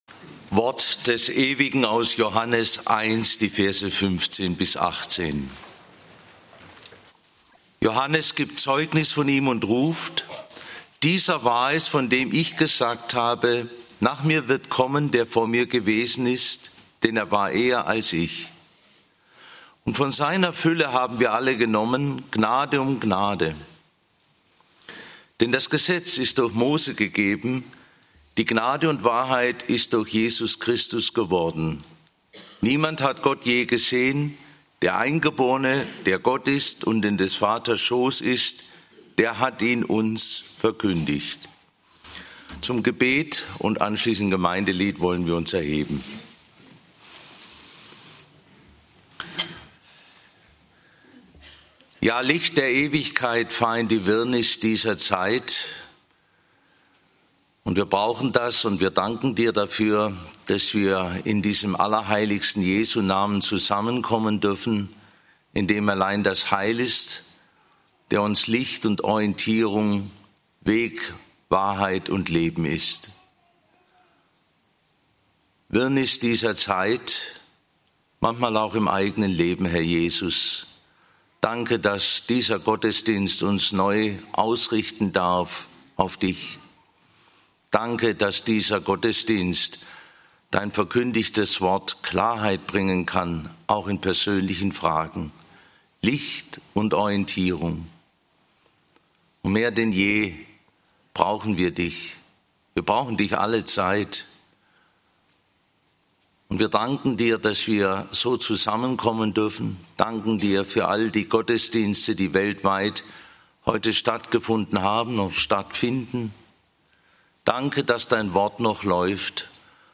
Weihnachten bei Johannes - Die Gnade (Joh. 1, 15-18) - Gottesdienst